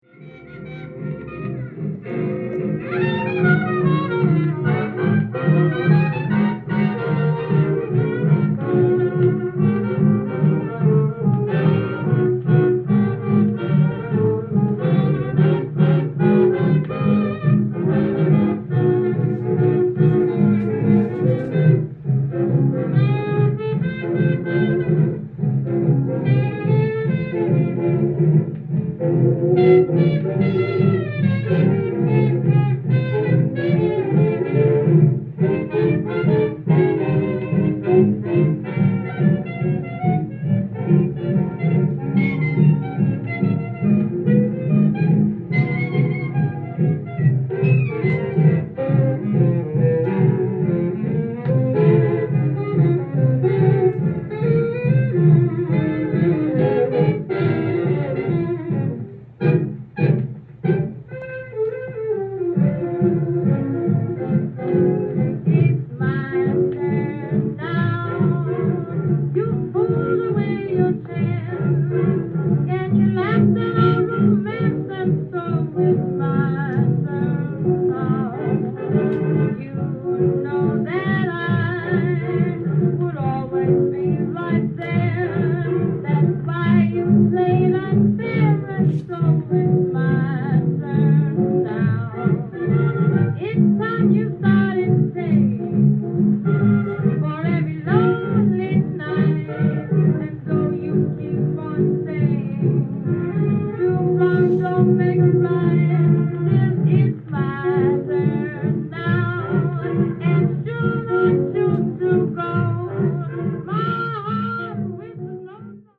LP
店頭で録音した音源の為、多少の外部音や音質の悪さはございますが、サンプルとしてご視聴ください。